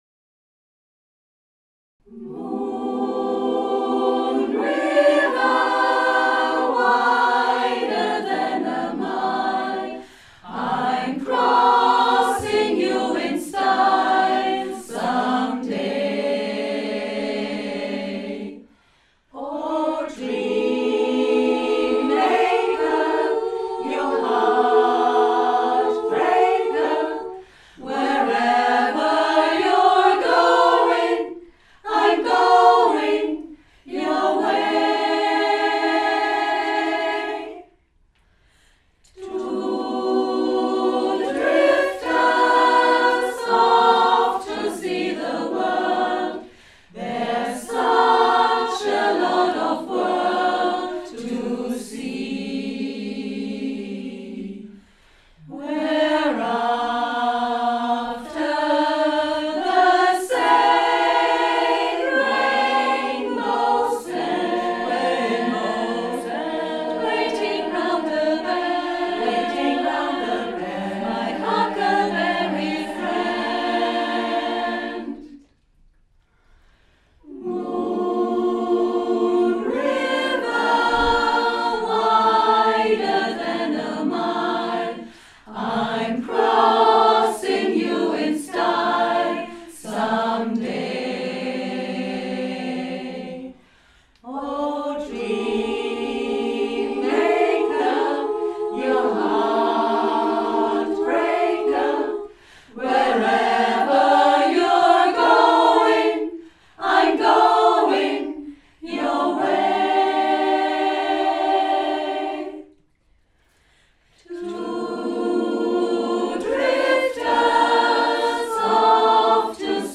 Der Frauenchor der Chrogemeinschaft besteht seit fast 50 Jahren und singt Lieder aus allen Zeiten und Genres.
Wir sind ein beständiger Chor, bestehend aus etwa 35 aktiven Sängerinnen zwischen 30 und 90 (!) Jahren, von denen einige schon seit 20, 30 und 40 Jahren zusammen singen.